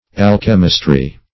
Alchemistry \Al"che*mis*try\, n.